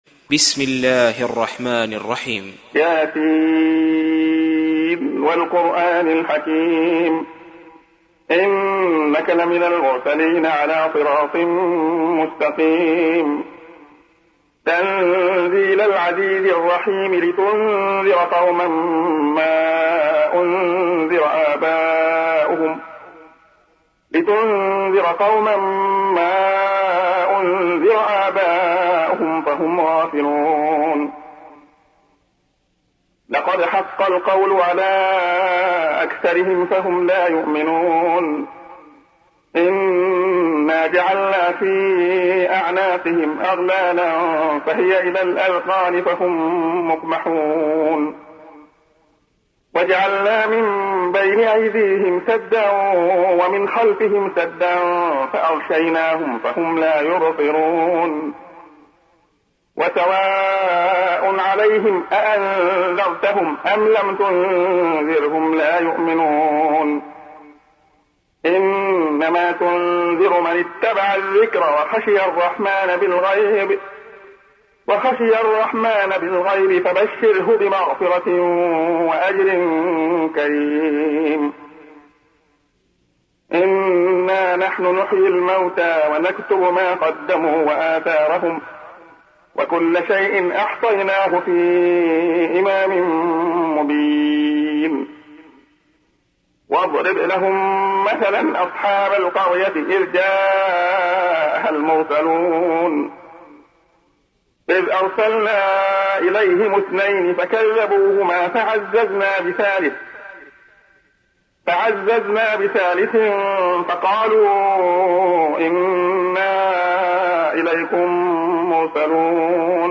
تحميل : 36. سورة يس / القارئ عبد الله خياط / القرآن الكريم / موقع يا حسين